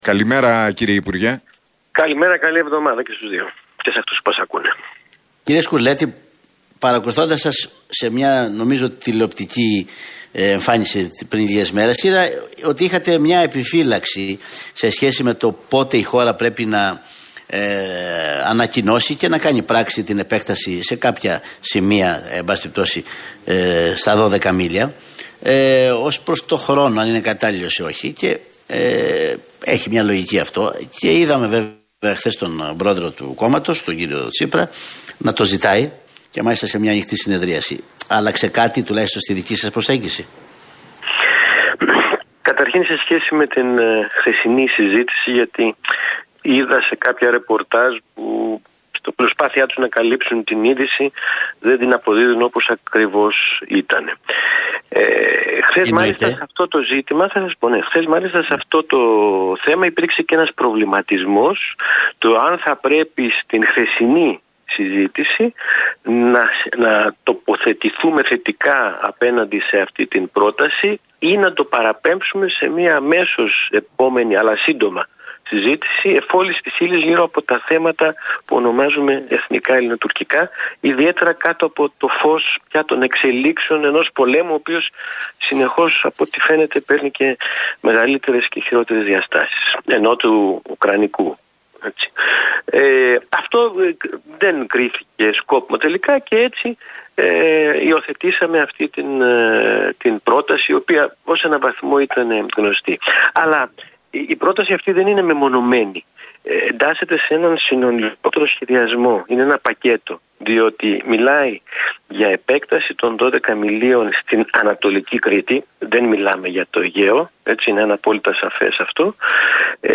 Σκουρλέτης στον Realfm 97,8: Η κυβέρνηση δεν διαπραγματεύεται όπως θα έπρεπε γύρω από τα εθνικά ζητήματα